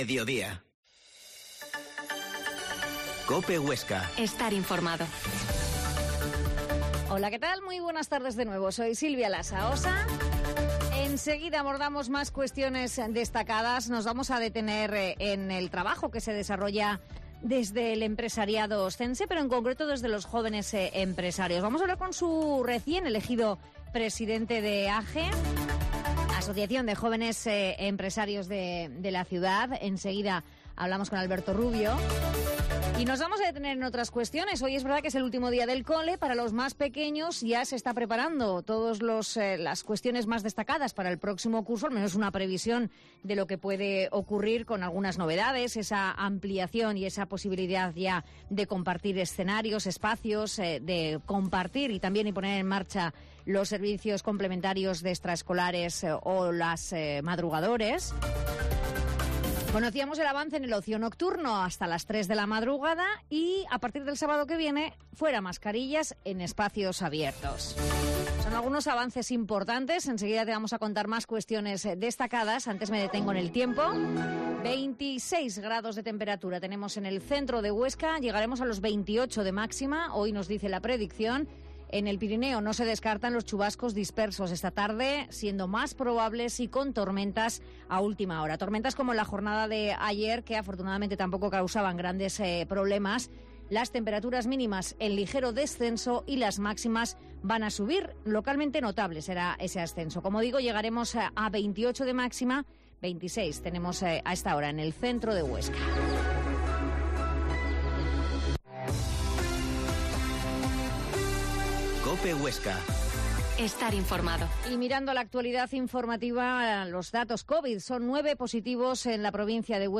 La Mañana en COPE Huesca - Informativo local Mediodía en Cope Huesca 13,20h.